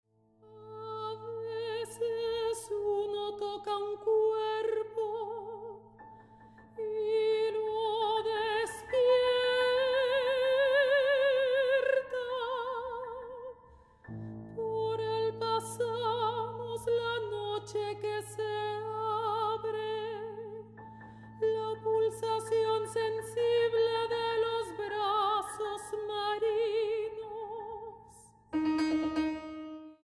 para contralto, piano, armonio y celesta.
mezzosoprano